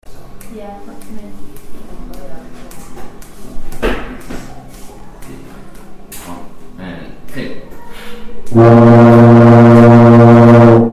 brass